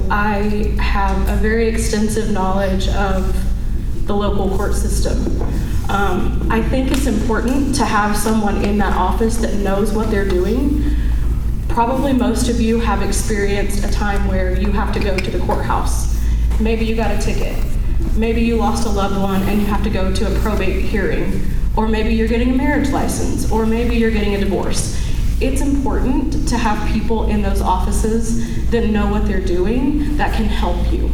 Court Clerk Candidates Speak at Osage County Forum